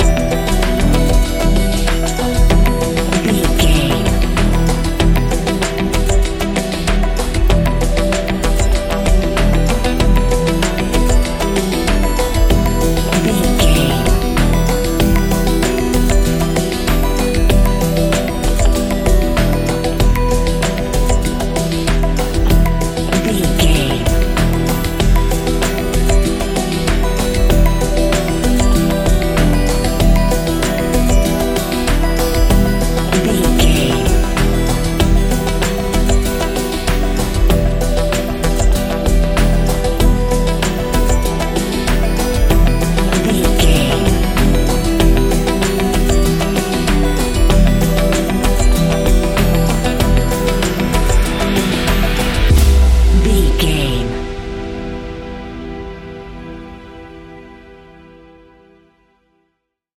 Ionian/Major
E♭
electronic
techno
trance
synthesizer
synthwave
instrumentals